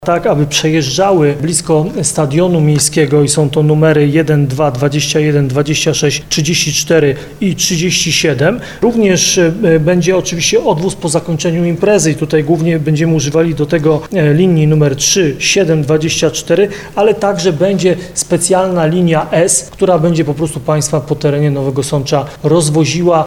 Planujemy też kursy powrotne linii 3, 7, 24 oraz specjalnej linii S, która rozwiezie mieszkańców po terenie Nowego Sącza – mówi Ludomir Handzel, prezydent Nowego Sącza.